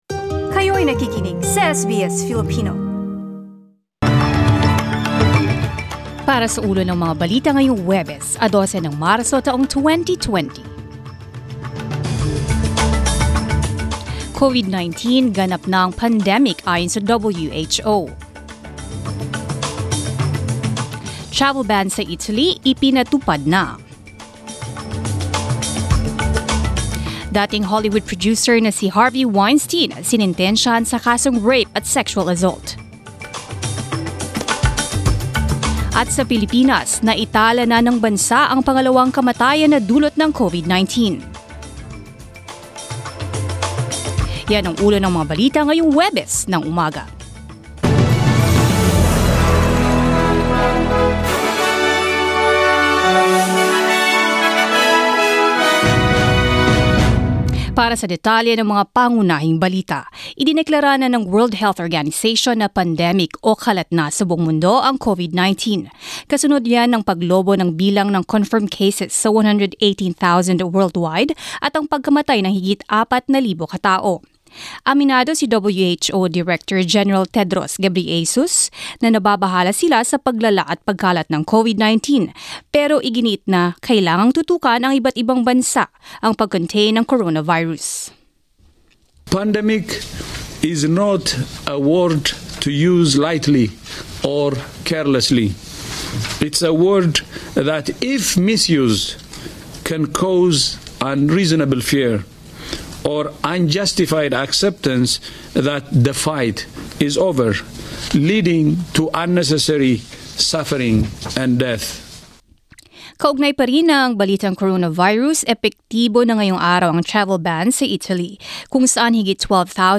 SBS News in Filipino, Thursday 12 March